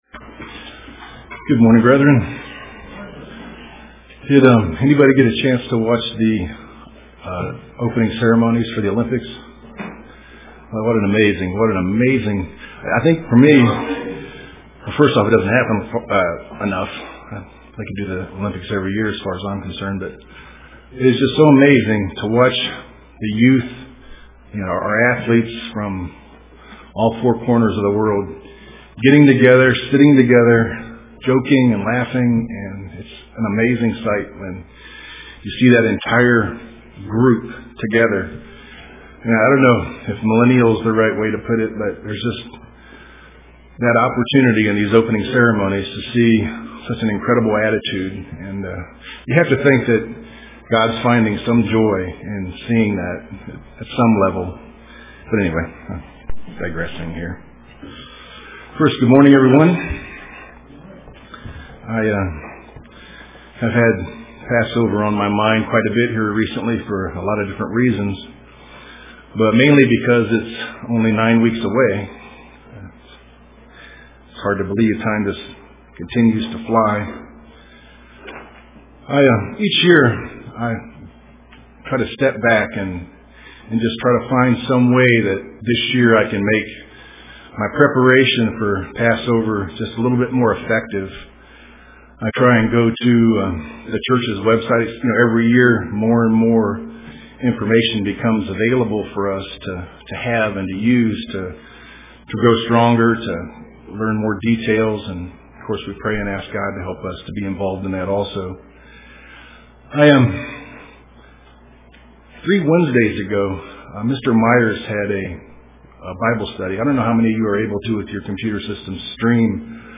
Print Things to Consider for Passover UCG Sermon Studying the bible?